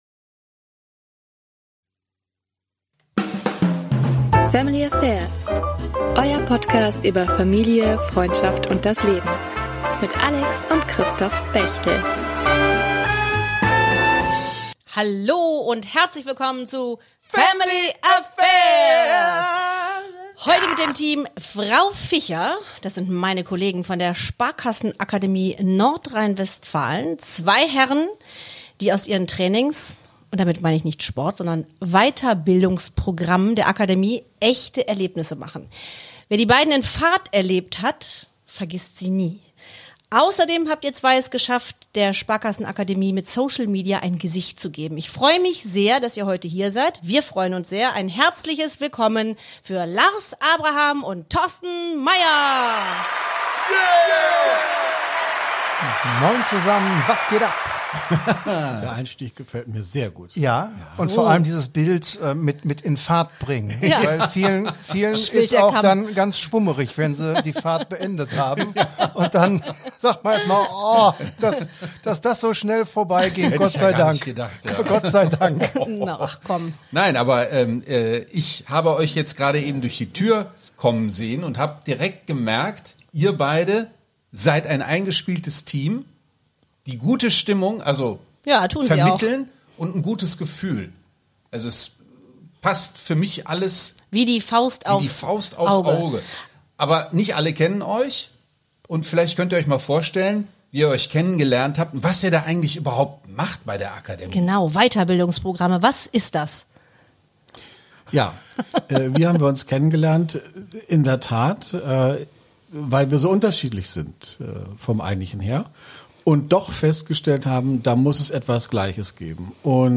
Ein witziges, offenes und gleichzeitig sehr ernsthaftes Gespräch.